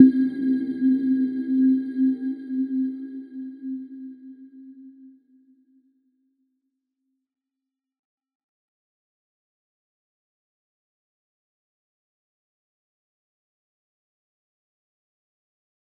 Little-Pluck-C4-f.wav